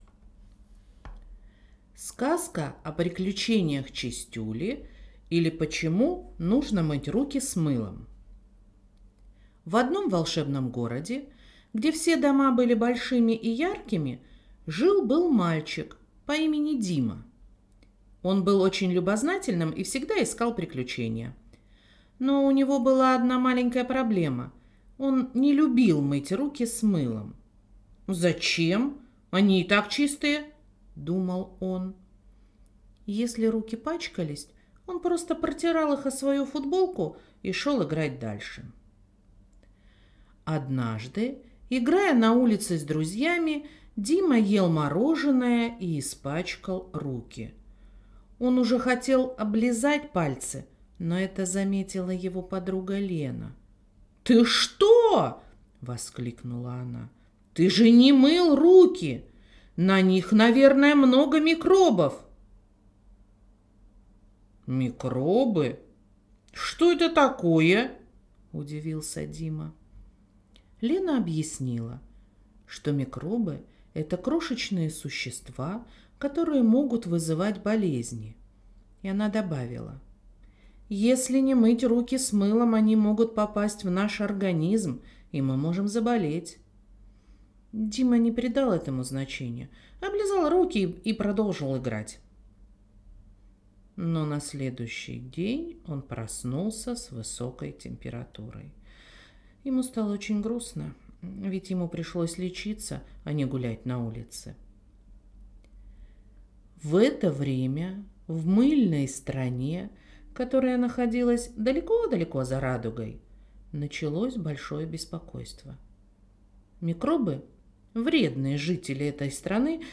skazka.mp3